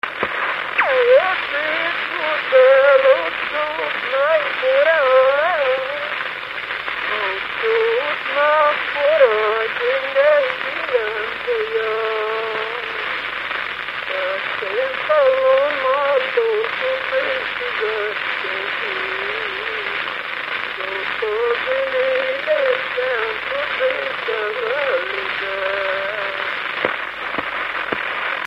Moldva és Bukovina - Moldva - Lészped
[A 2. versszak szövege érthetetlen]
Stílus: 8. Újszerű kisambitusú dallamok
Kadencia: X (X) X 1